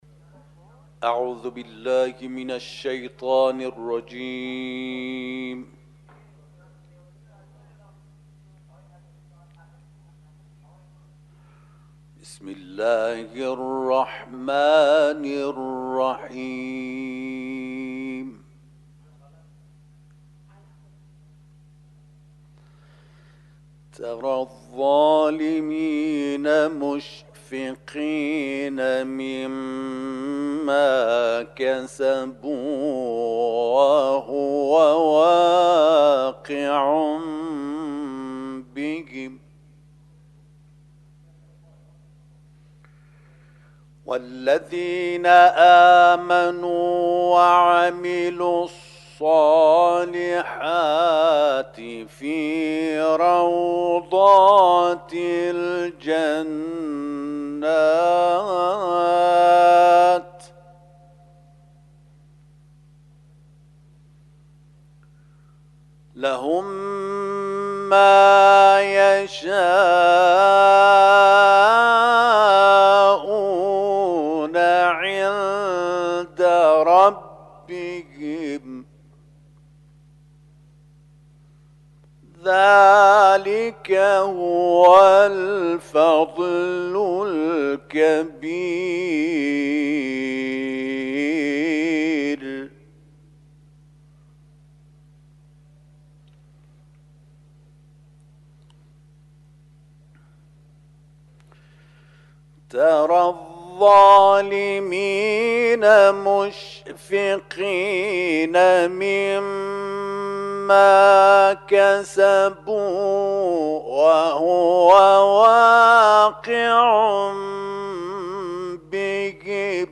صوت تلاوت آیاتی از سوره‌های‌ «شوری» و «علق»